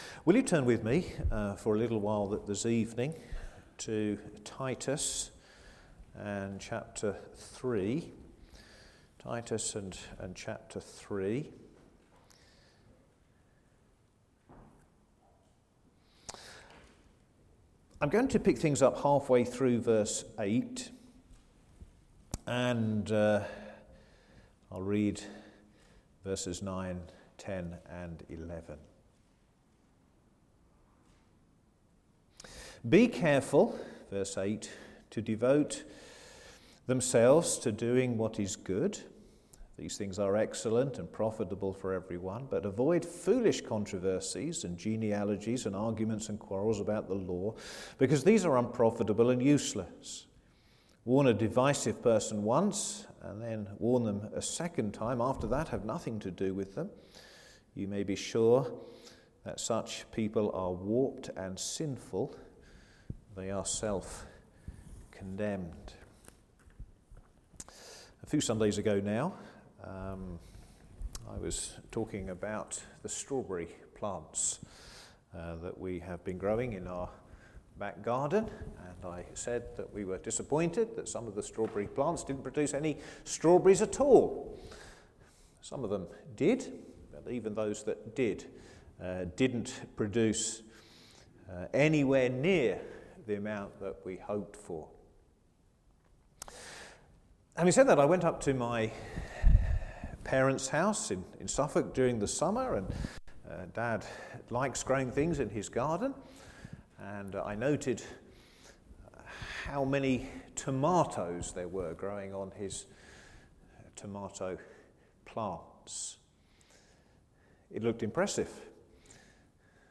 Sermon
Service Evening